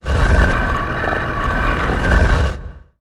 kaimen.mp3